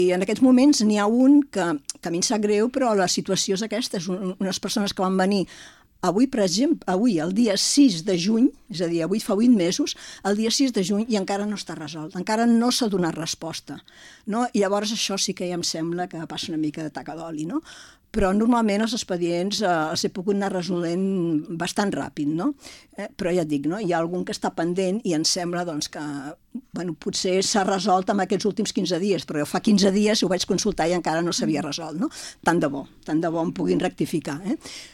Un any després de la seva primera entrevista com a Síndica de Greuges, la Cel·la Fort torna a La Local per fer balanç del seu primer any al càrrec i comentar l’informe que va presentar en el ple del mes de gener.